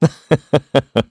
Clause_ice-Vox-Laugh_kr_c.wav